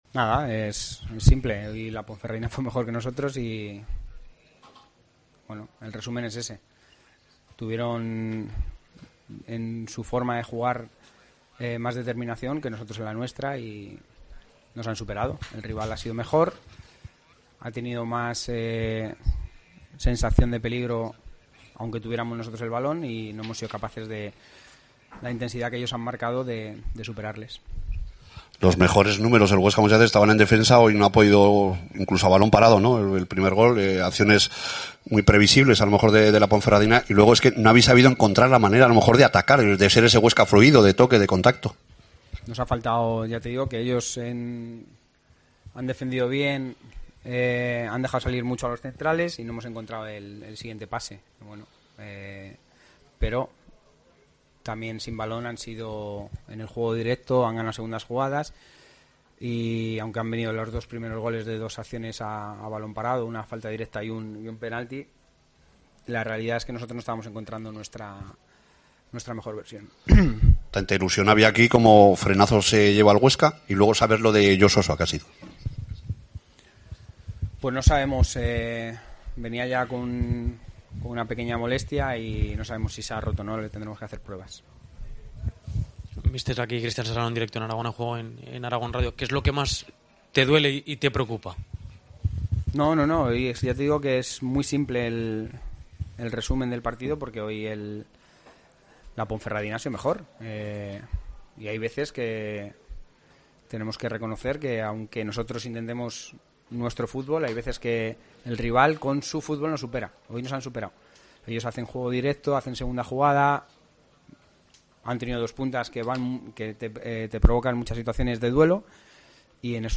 Escucha aquí el postpartido con declaraciones de Míchel Sánchez